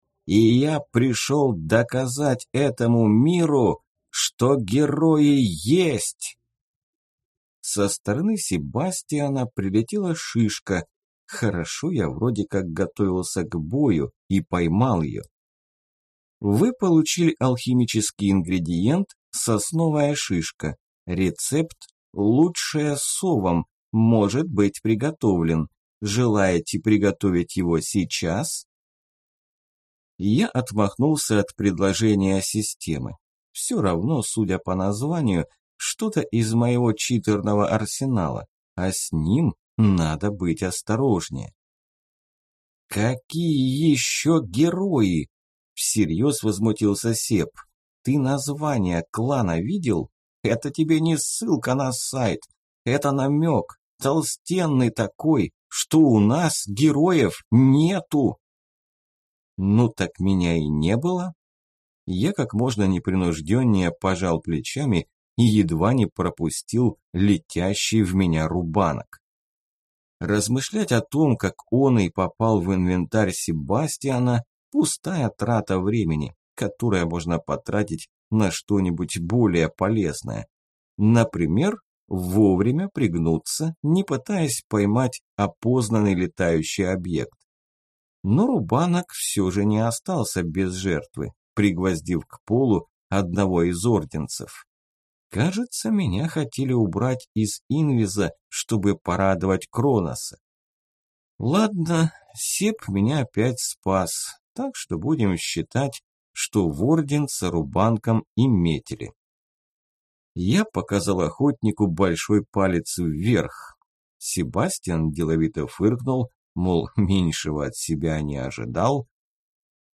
Аудиокнига Герои есть | Библиотека аудиокниг